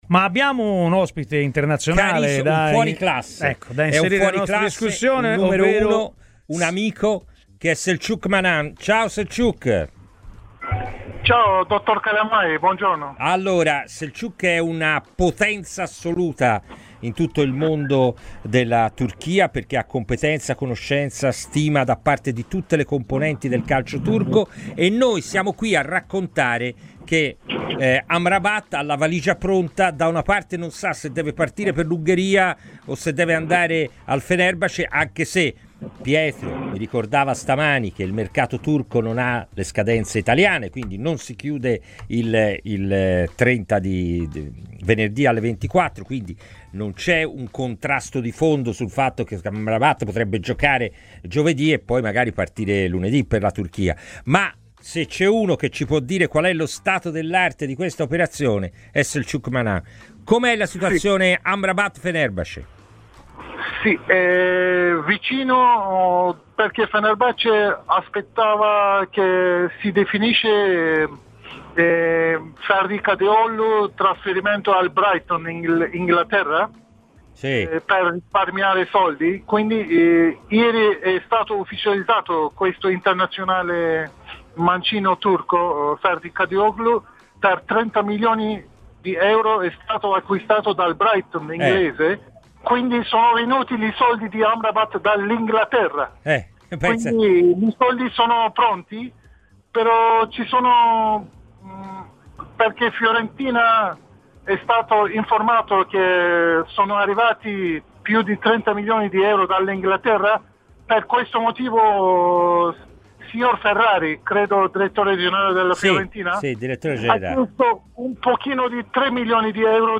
Il giornalista turco